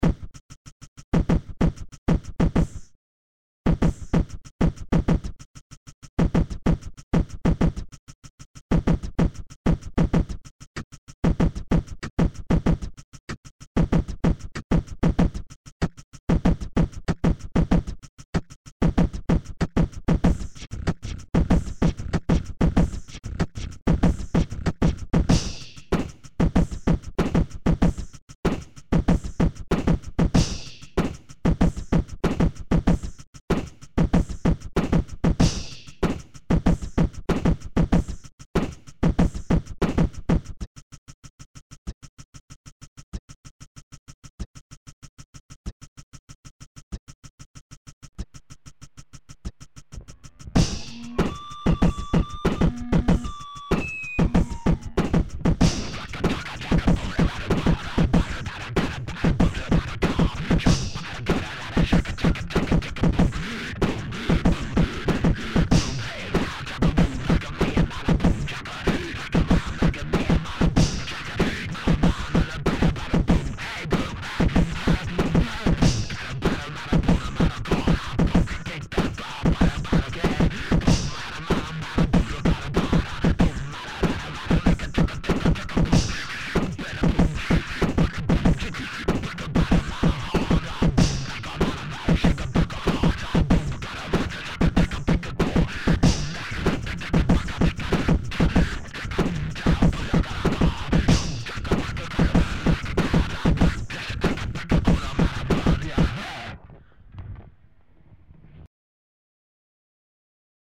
Metal was creeping back into my musicality.